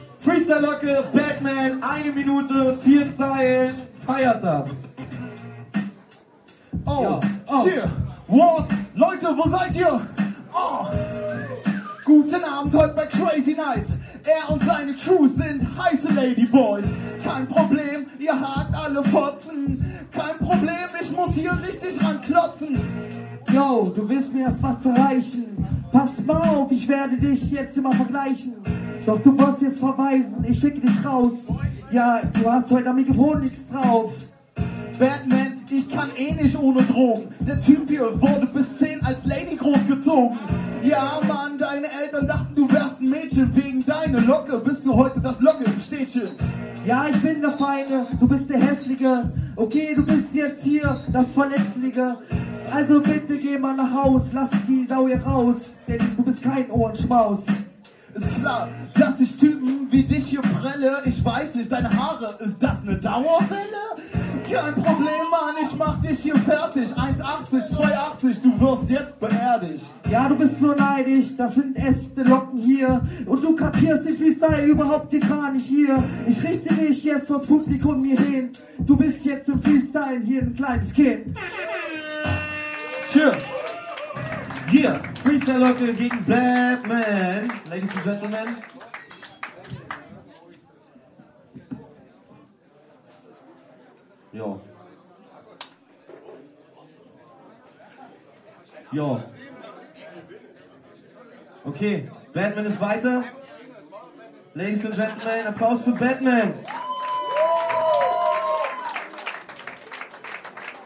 an den Turntables